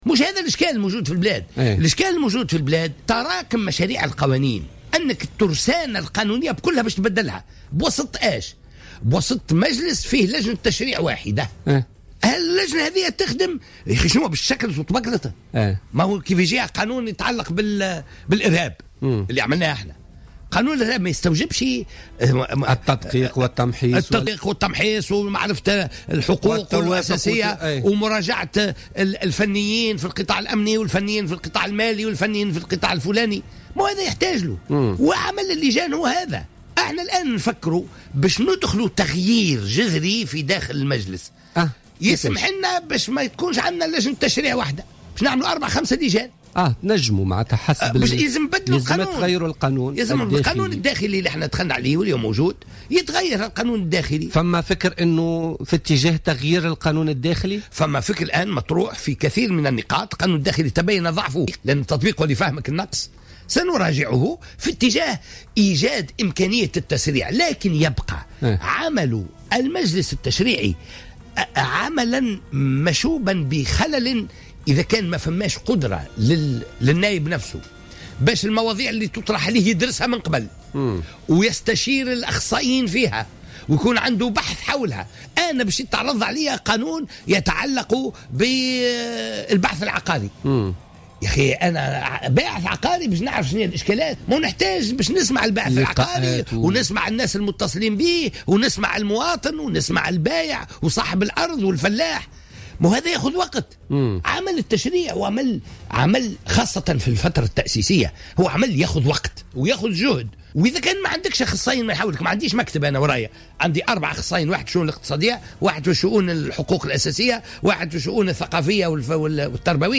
أعلن عبد الفتاح مورو نائب رئيس مجلس نواب الشعب في تصريح للجوهرة أف أم في برنامج بوليتكا لليوم الأربعاء 09 مارس 2016 أن هناك نية لإحداث تغيير في النظام الداخلي للمجلس والاتجاه إلى إحداث لجان تشريعية جديدة بهدف التسريع في دراسة ومناقشة القوانين.